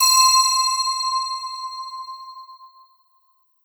Beach Bells.wav